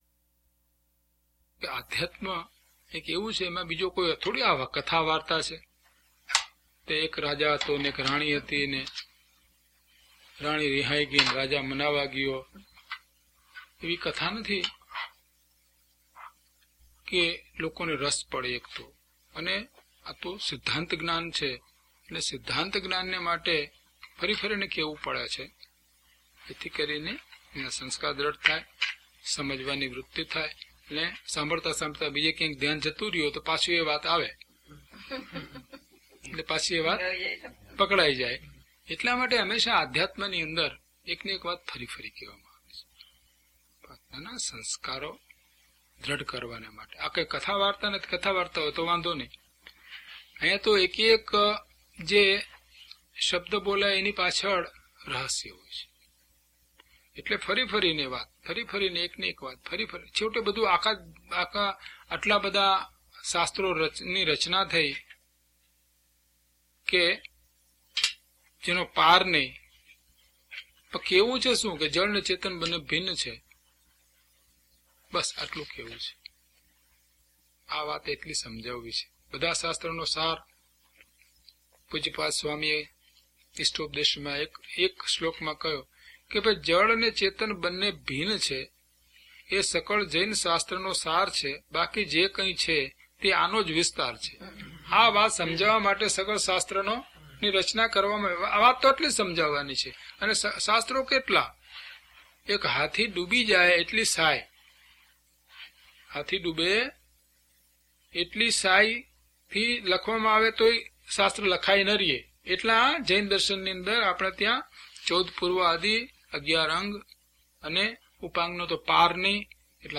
Audio title: DHP034 Jad Bhave Jad Pariname Part 1 - Pravachan.mp3
DHP034 Jad Bhave Jad Pariname Part 1 - Pravachan.mp3